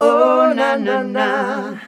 OHNANANAH.wav